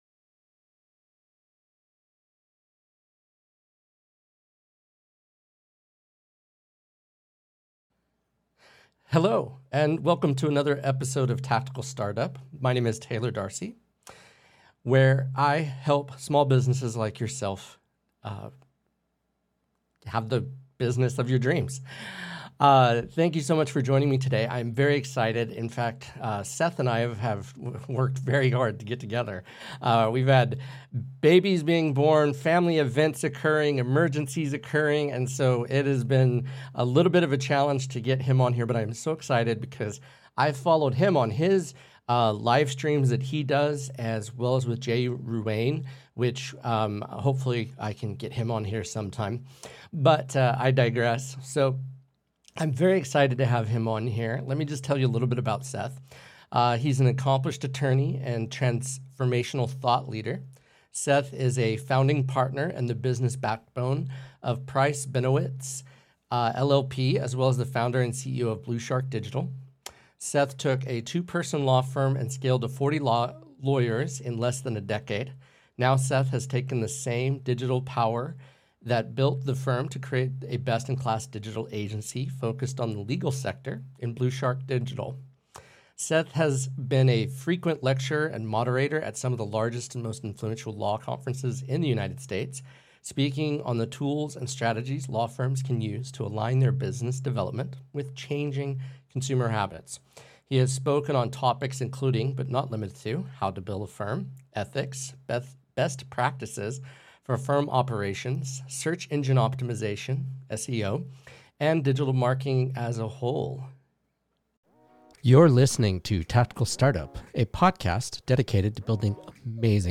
Shure SM7B Microphone
Rode Rodecaster Pro